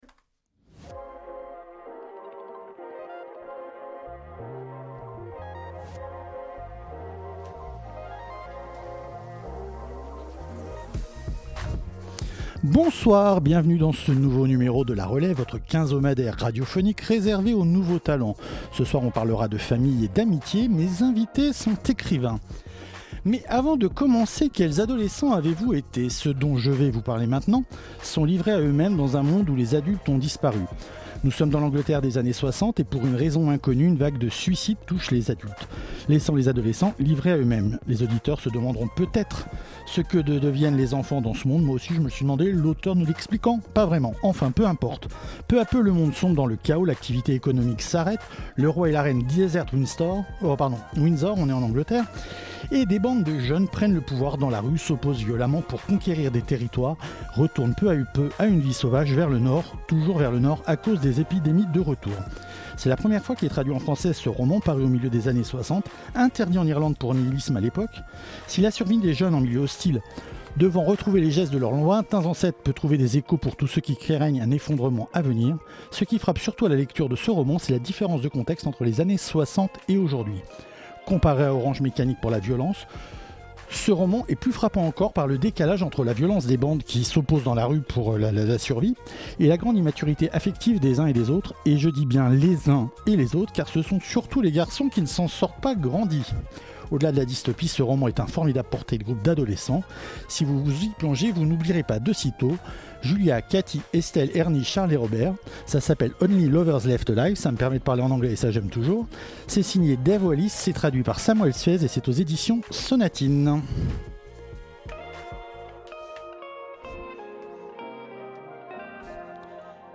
Type Entretien Culture